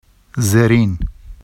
[zerin] adj lower